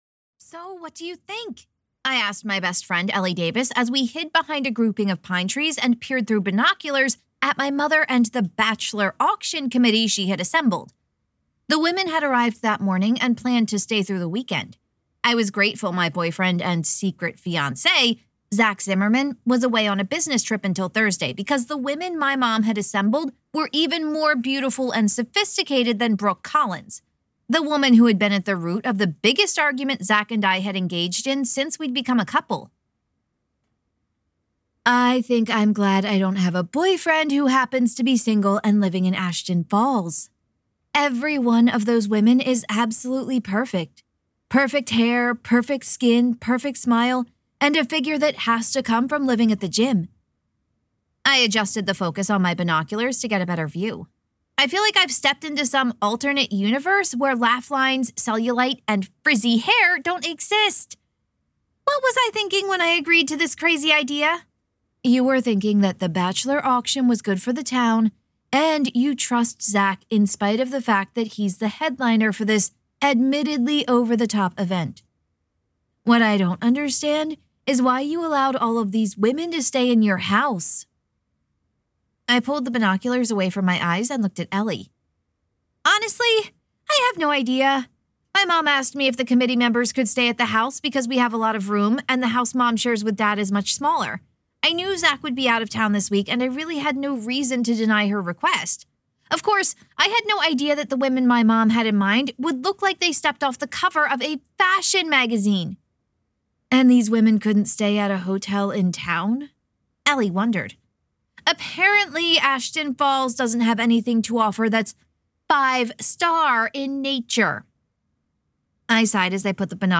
Book 10 RetaIl Audio Sample Turkeys, Tuxes, and Tabbies (Zoe Donovan Mystery).mp3